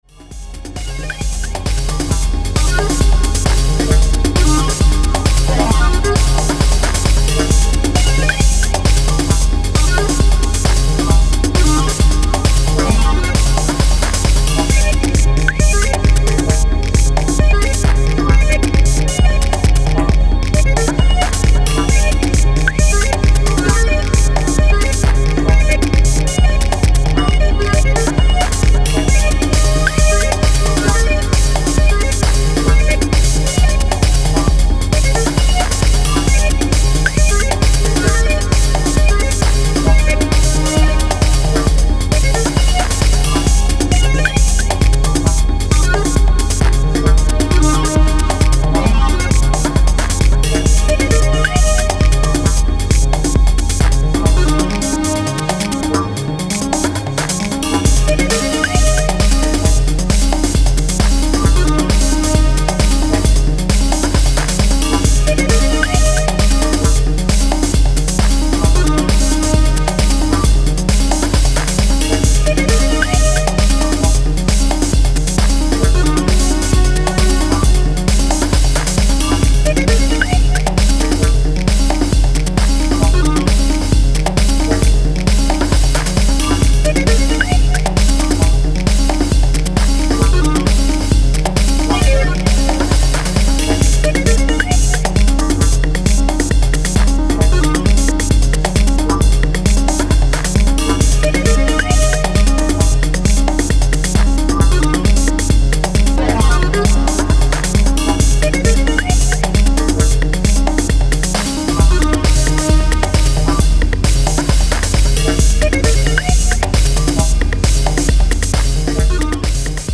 ポジティブなリードおよびアルペジオとボンゴの軽快なグルーヴに心躍る